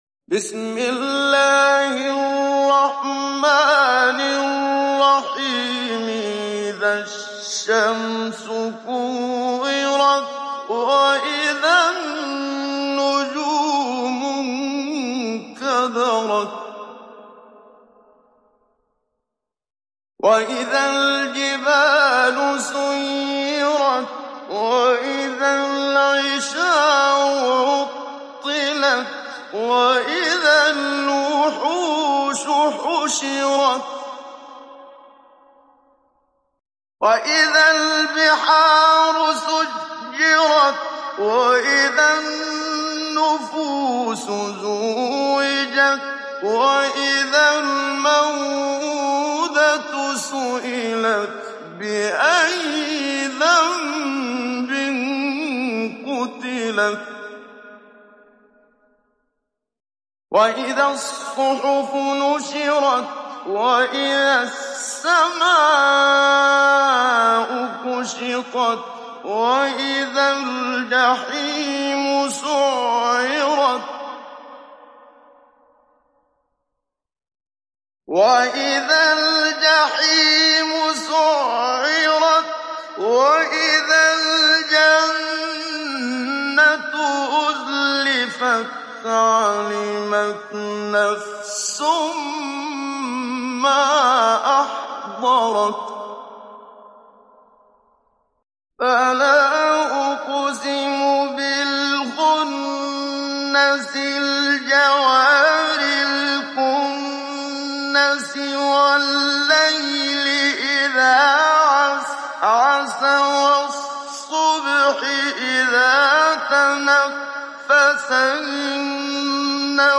دانلود سوره التكوير mp3 محمد صديق المنشاوي مجود (روایت حفص)
دانلود سوره التكوير محمد صديق المنشاوي مجود